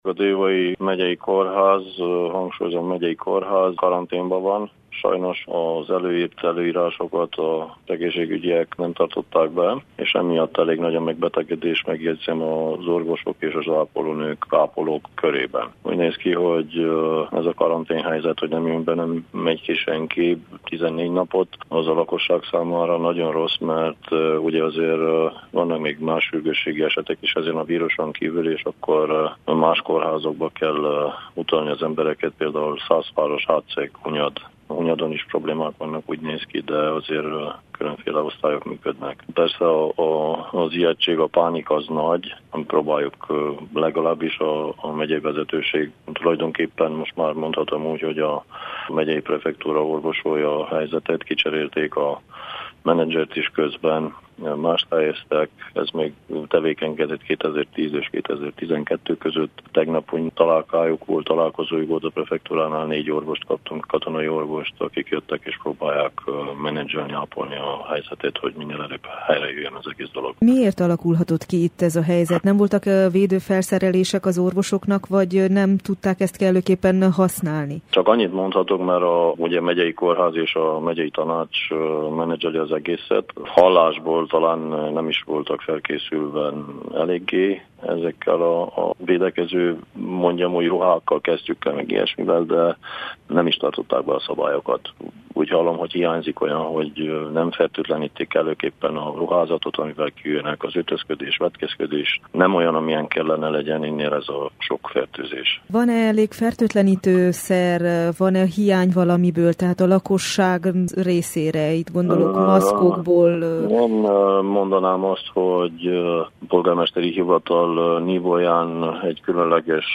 Pogocsán Ferdinánd Zoltánt, Déva alpolgármesterét kérdezte